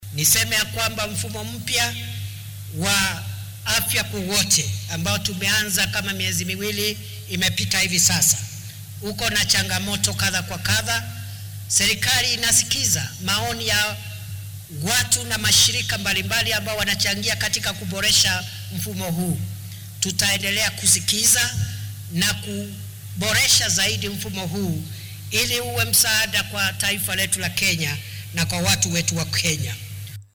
Xilli uu khudbad ka jeedinayay xafladda qalin jabinta ee machadka lagu barto culuunta caafimaadka ee dalka ee KMTC ayuu Kindiki hoosta ka xarriiqay in dowladdu ay ku dadaalaysa xallinta caqabadaha ku gadaaman nidaamkan.